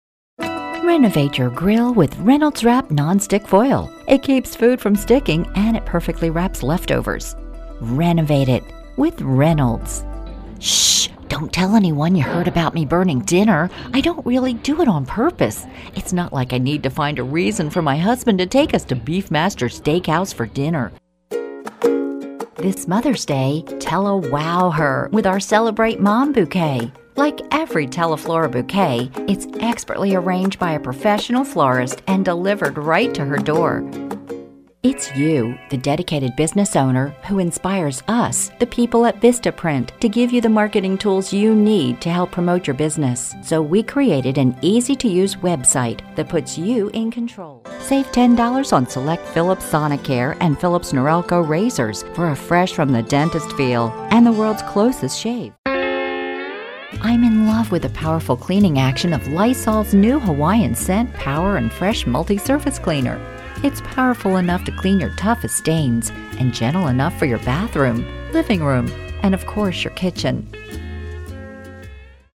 Sprechprobe: Werbung (Muttersprache):
Mid range voice with energy, conversational, even character voices